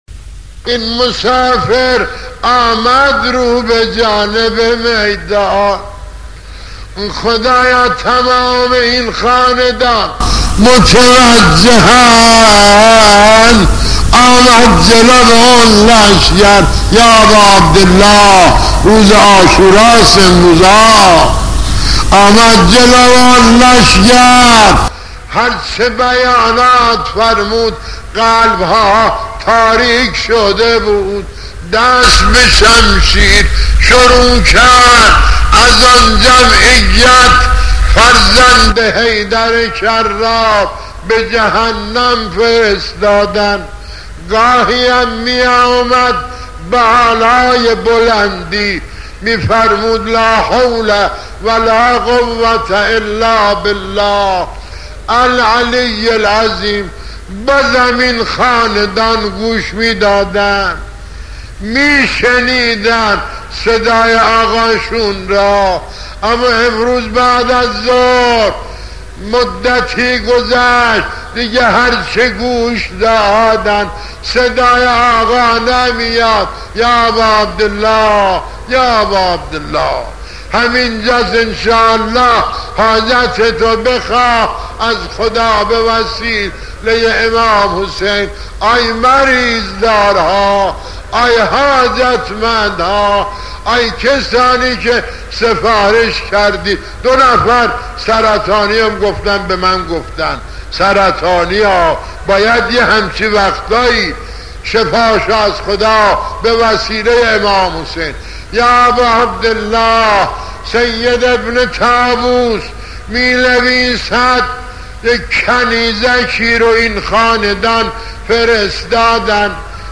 مجموعه مداحی های قدیمی